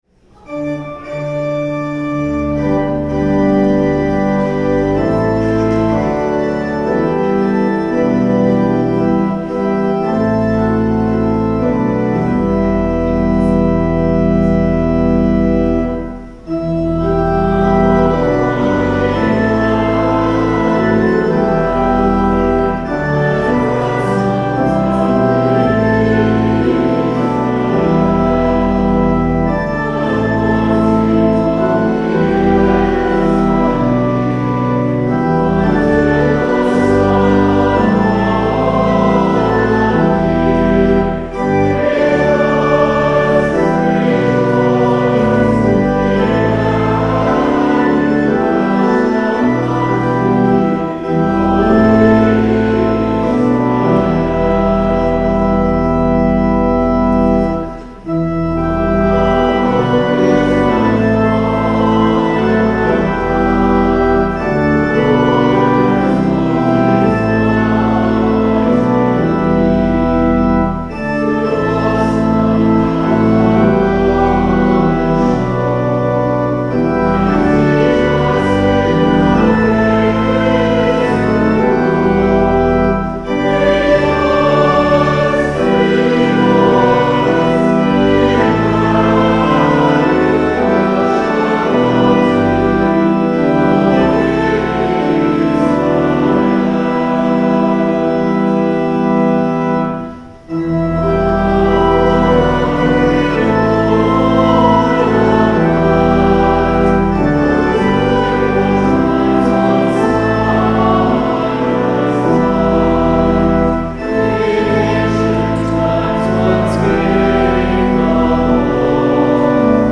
Hymn: O Come, O Come, Emmanuel (Common Praise #89)
The Lord’s Prayer (sung) Hymn: O love, how deep, how broad, how high (Common Praise #628)